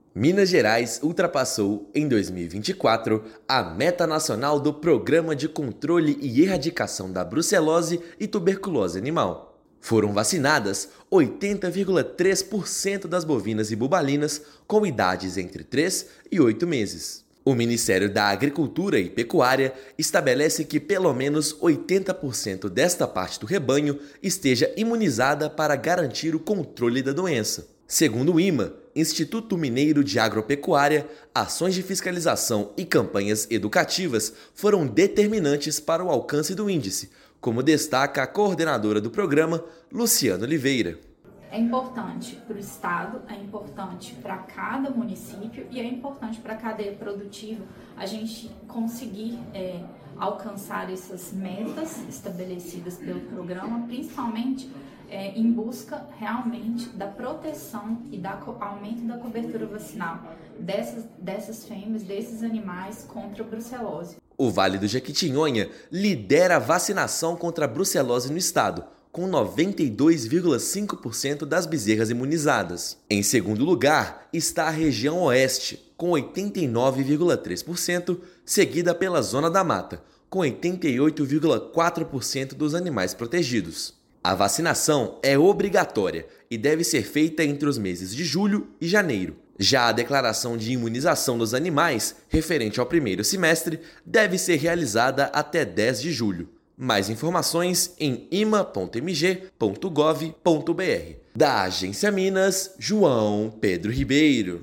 IMA cumpre exigência do Ministério da Agricultura e fortalece o controle da doença no estado. Vale do Jequitinhonha, Oeste de Minas e Zona da Mata lideram ranking de vacinação. Ouça matéria de rádio.